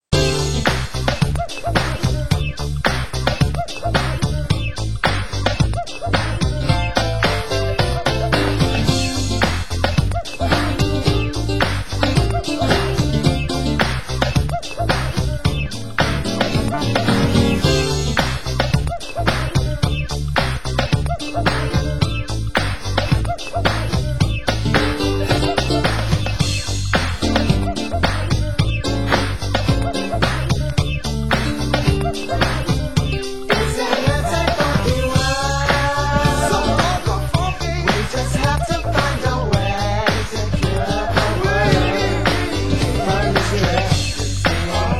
Genre: Soul & Funk